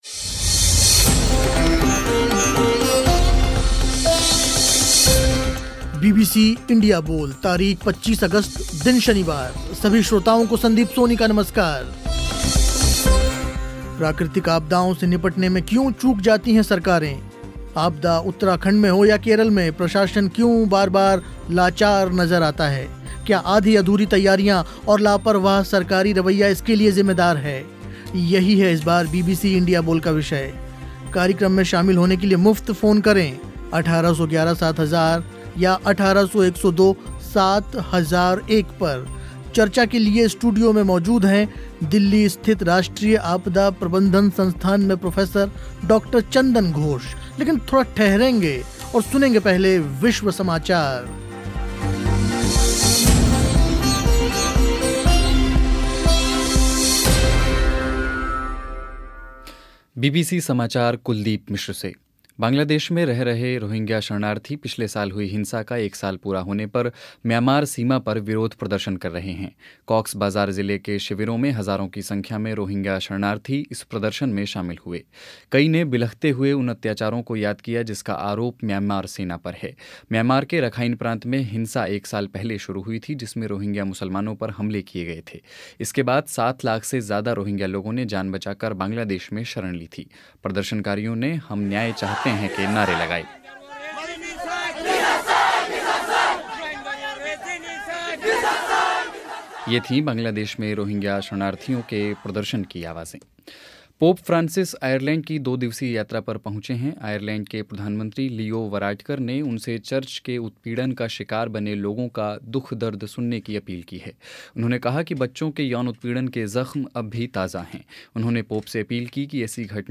इंडिया बोल में चर्चा हुई इसी विषय पर